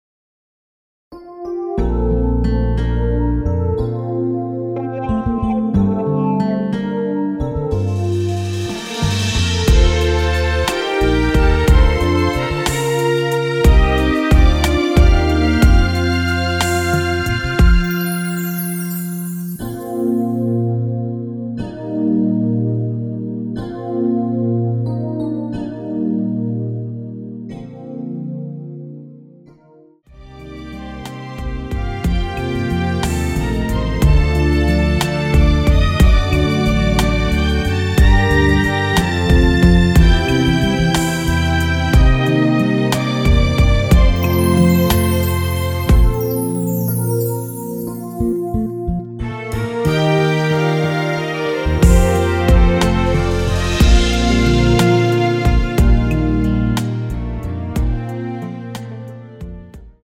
Bb
MR 편곡도 깔끔하고 좋아요!!
앞부분30초, 뒷부분30초씩 편집해서 올려 드리고 있습니다.
중간에 음이 끈어지고 다시 나오는 이유는